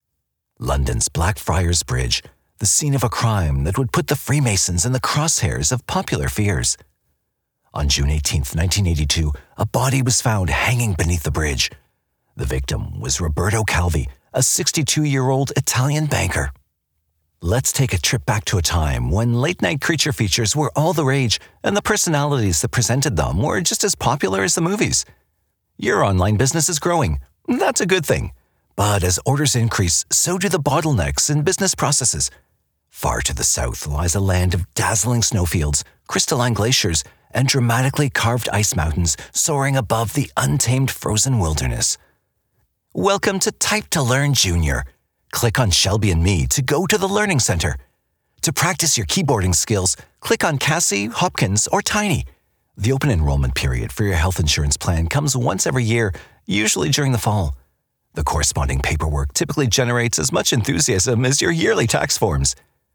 Narration demo reel
English - Midwestern U.S. English
Middle Aged